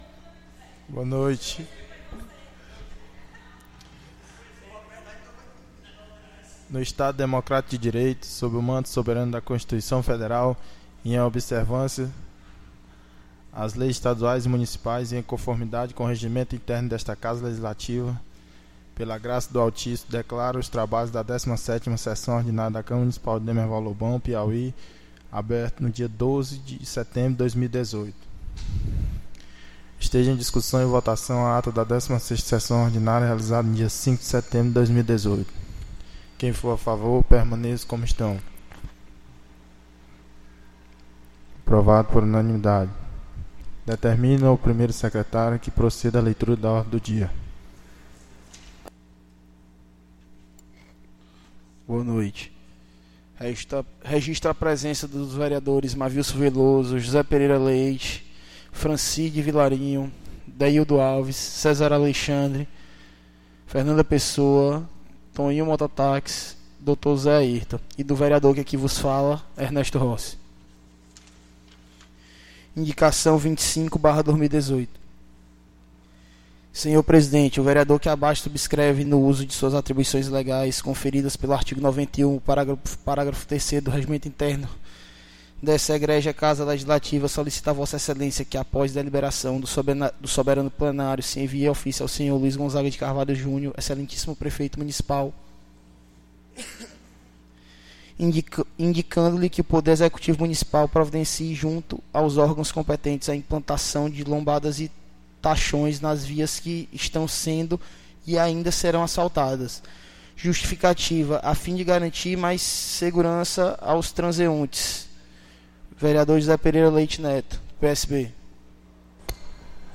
17ª Sessão Ordinária 12/09/2018